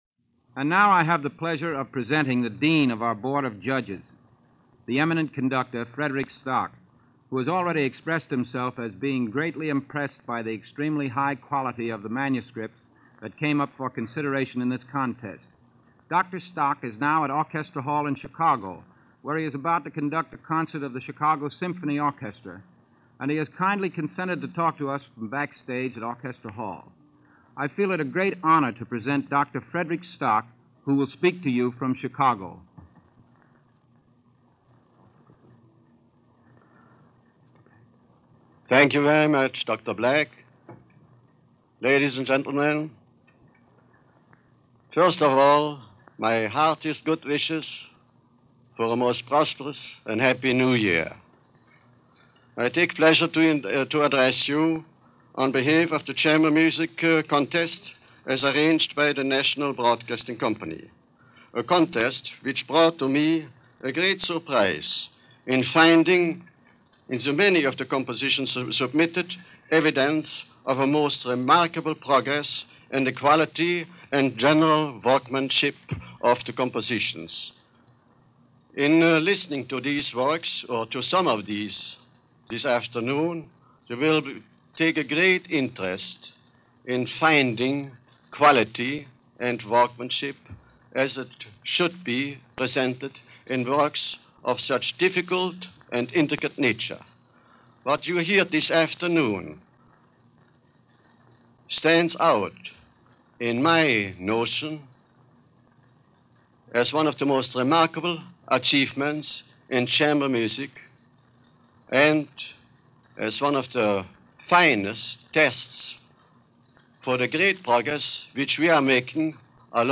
String Quartet
Also, as a sideline, there are remarks by the legendary Conductor of the Chicago Symphony Frederick Stock , one of the few examples of his speaking voice.
The Stillman Quartet is performed by the NBC String Quartet, who were also friends and colleagues of the composer.